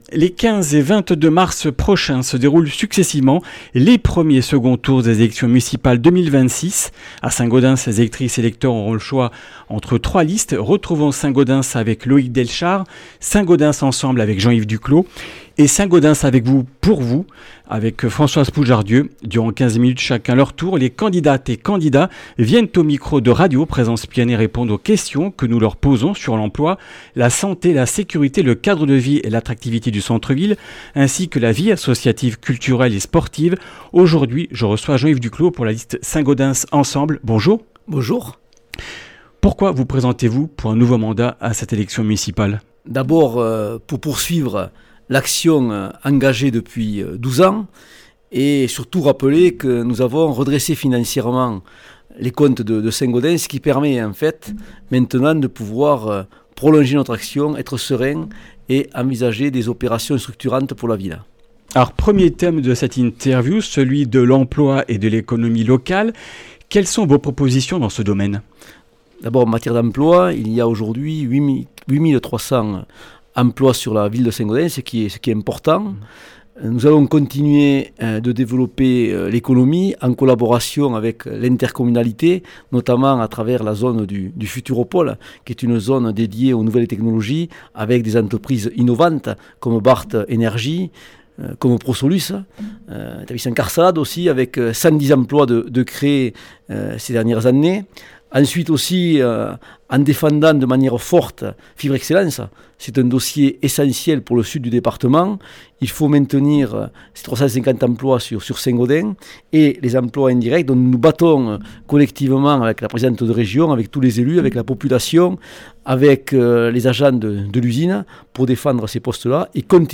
Comminges Interviews du 11 mars